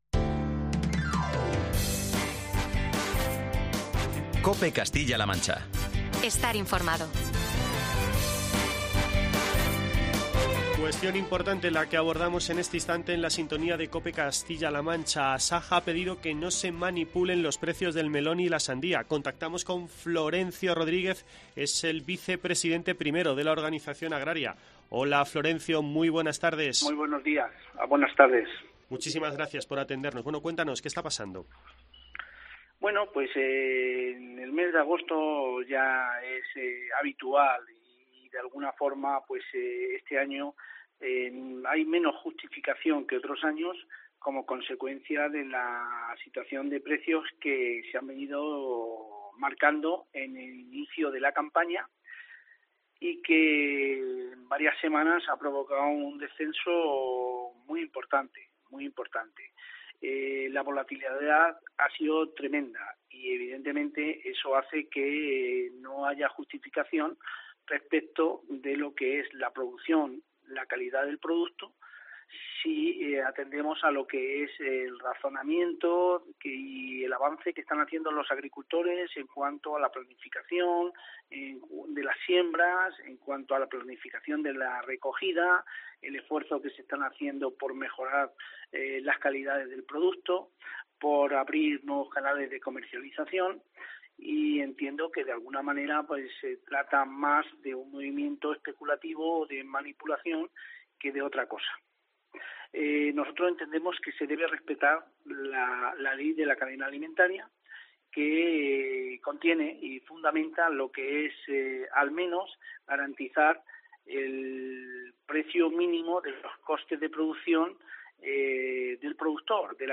En la parte superior de esta noticia puedes encontrar todos los detalles de la entrevista